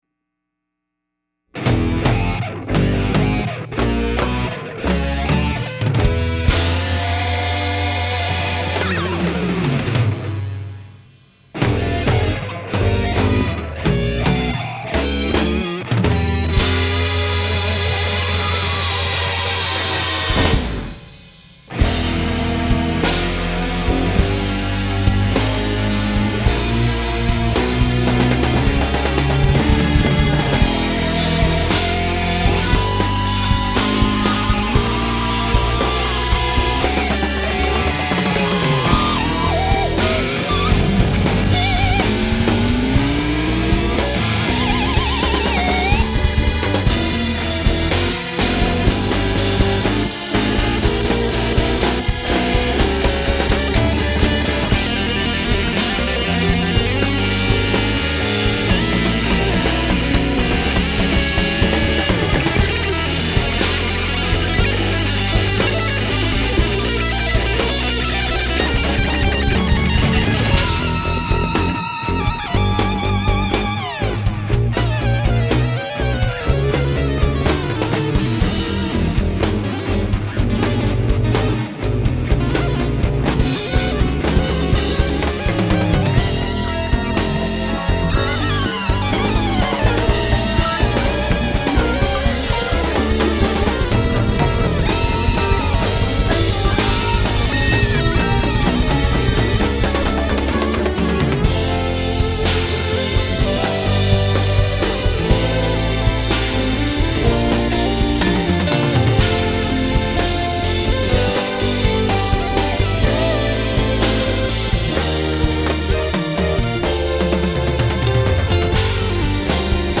drums
bass
guitarist
vocalist